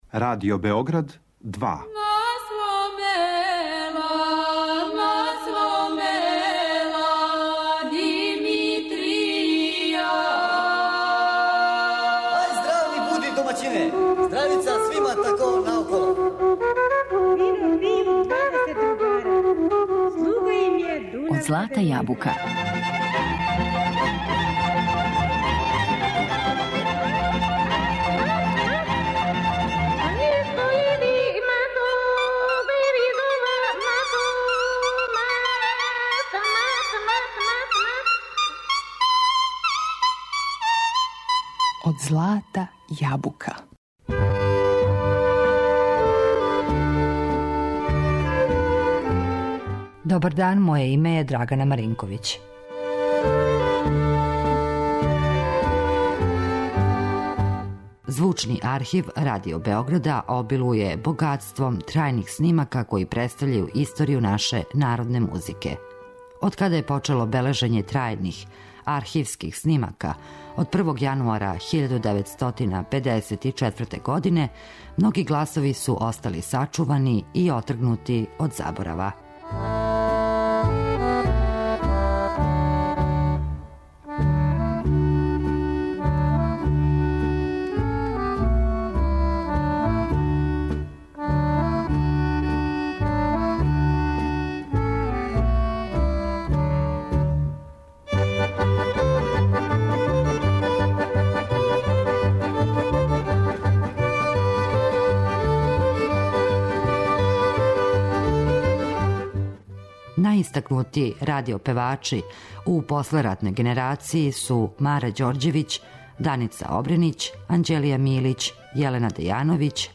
Звучни архив Радио Београда обилује богатством трајних снимака који представљају историју наше народне музике.